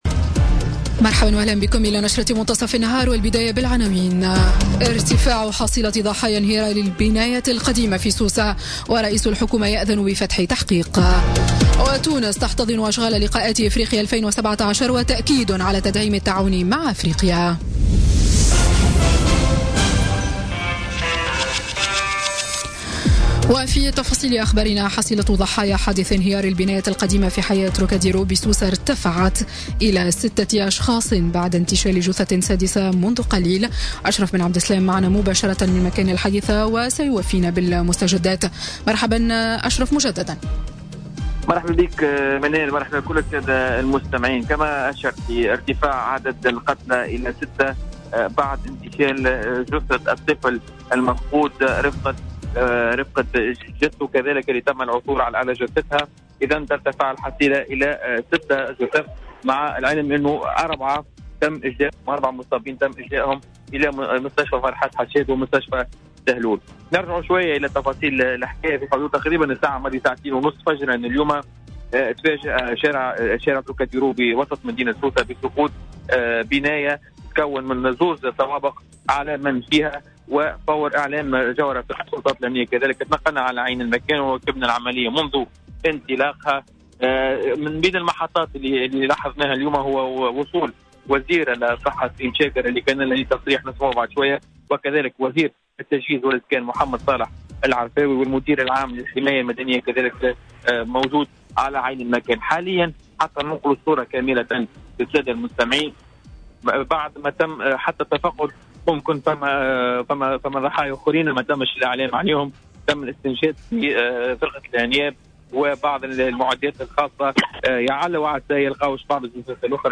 نشرة أخبار منتصف النهار ليوم الخميس 5 أكتوبر 2017